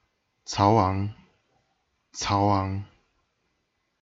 pronunciation) (c. 177 – February or March 197),[a] courtesy name Zixiu, was the eldest son of Cao Cao, a warlord who rose to power towards the end of the Han dynasty and laid the foundation of the state of Cao Wei in the Three Kingdoms period of China.